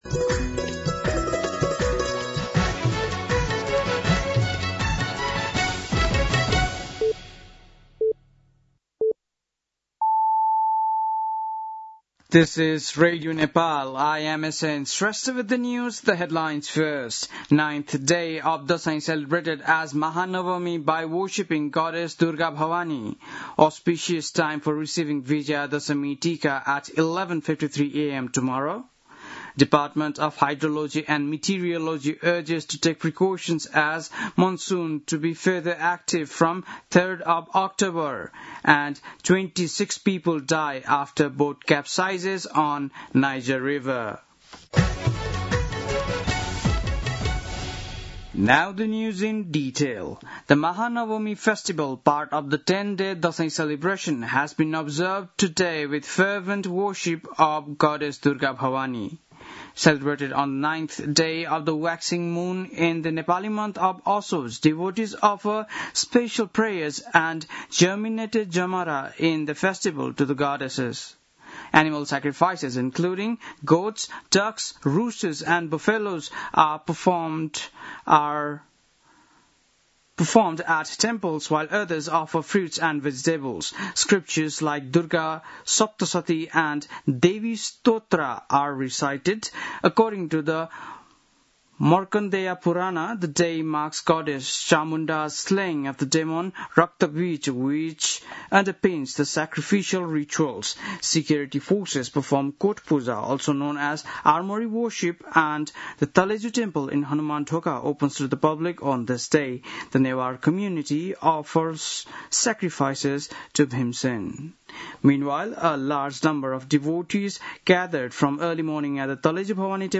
बेलुकी ८ बजेको अङ्ग्रेजी समाचार : १५ असोज , २०८२